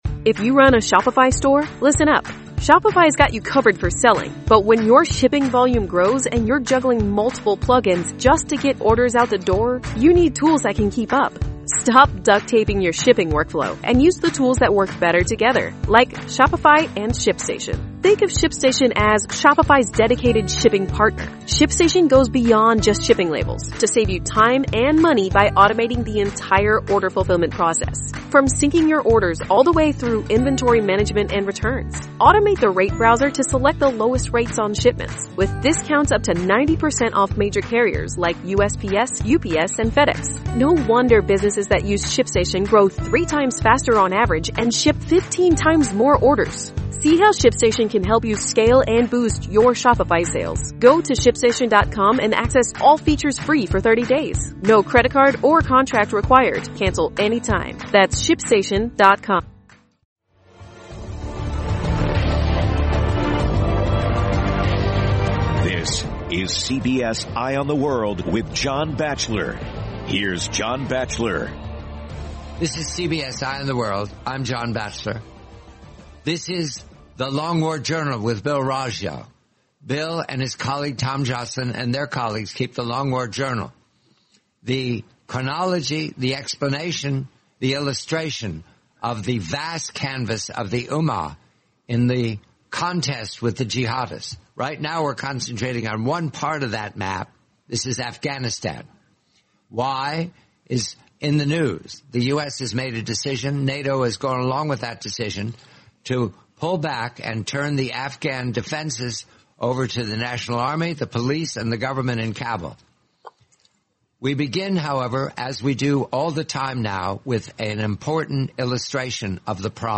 The complete, forty-minute interview, July 26, 2021.